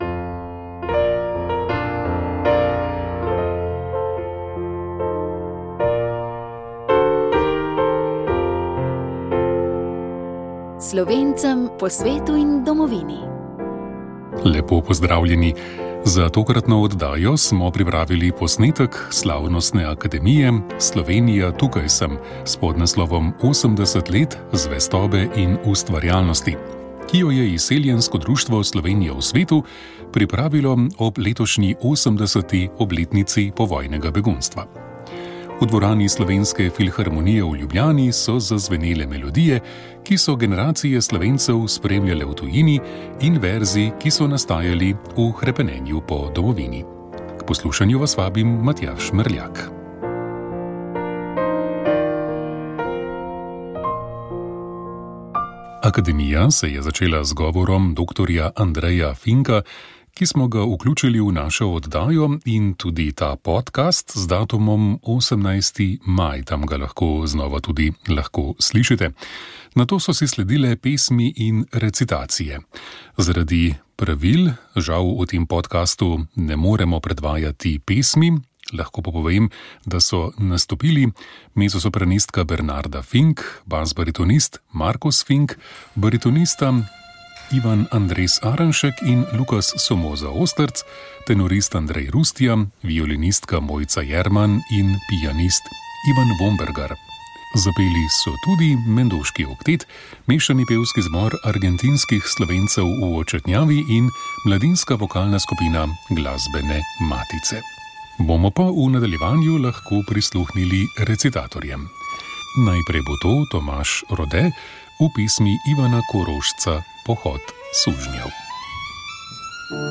V tokratni oddaji smo slišali izjave mladih pevcev, ki so razmišljali o tem, zakaj pojejo.